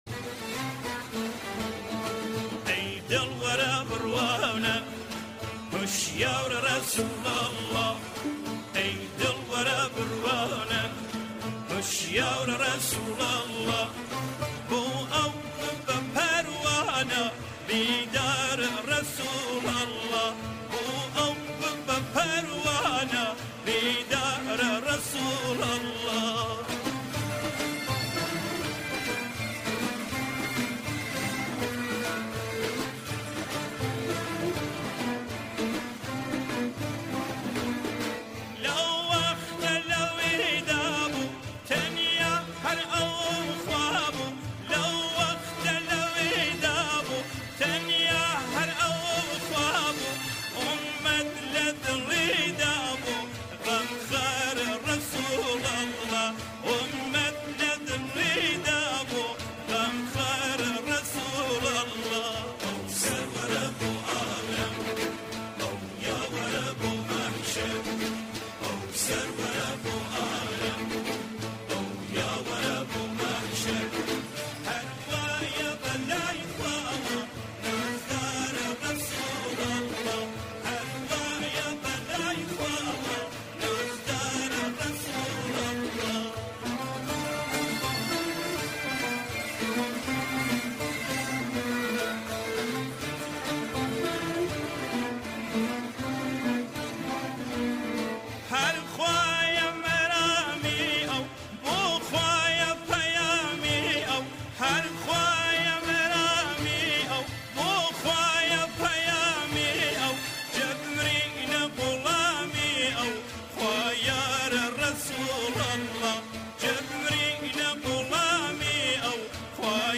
گۆرانییەک کوردی به ناوی رسول الله (د.خ)